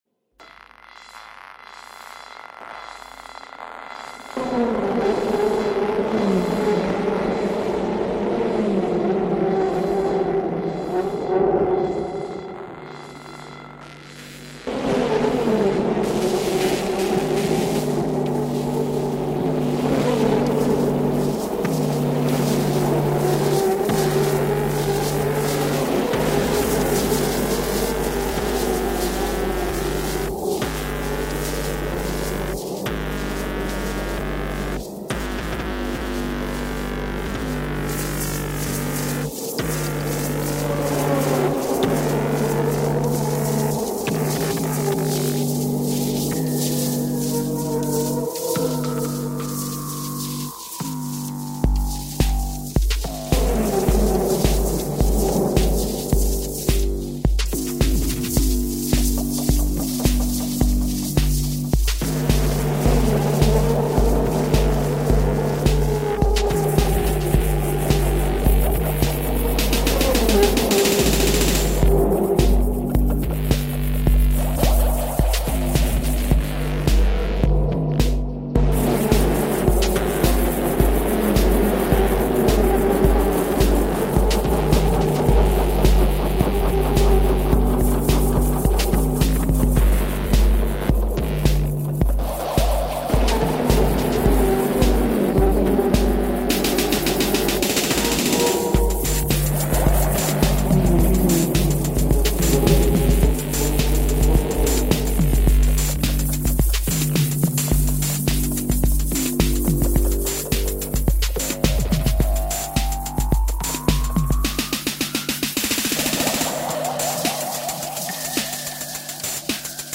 Singapore street car racing reimagined